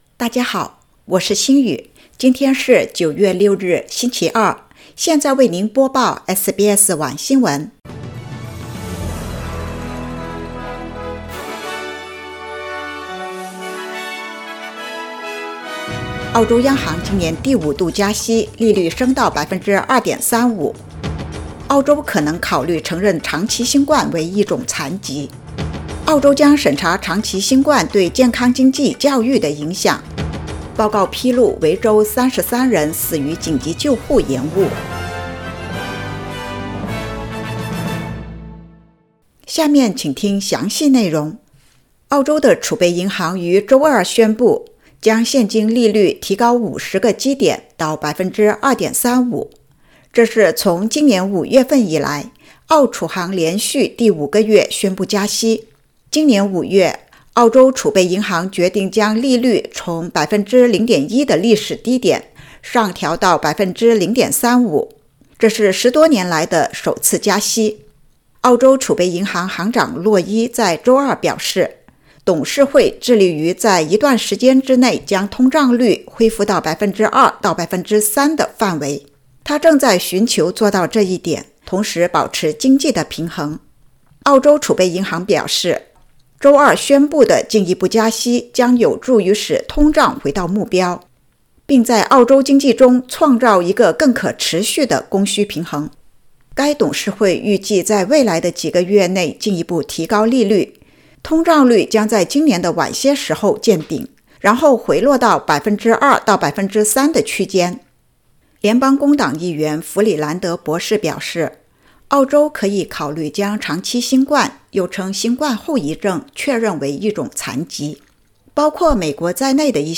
SBS Mandarin evening news Source: Getty / Getty Images